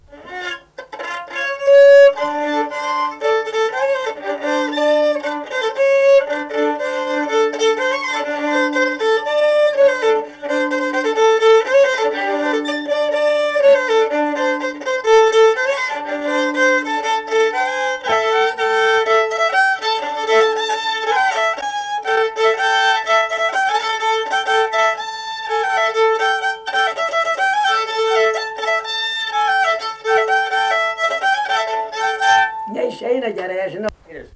daina